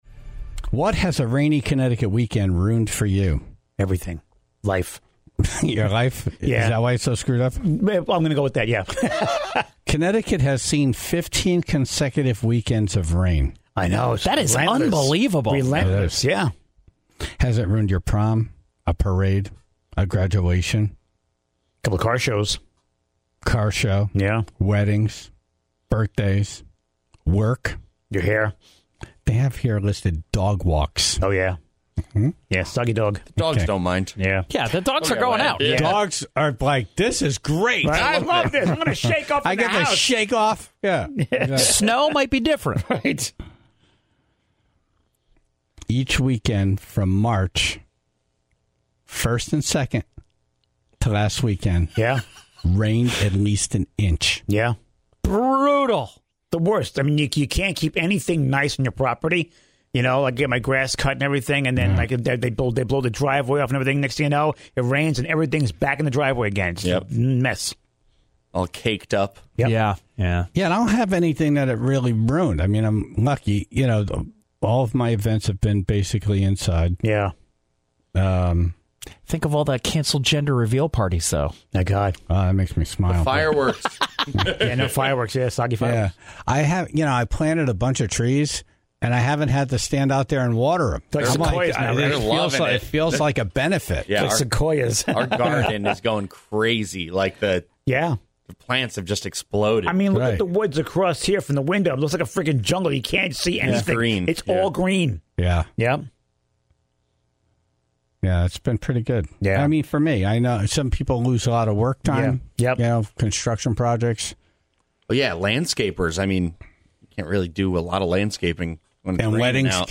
in studio
and took some calls from the Tribe about crazy drivers, and the speeding traps they've been caught in.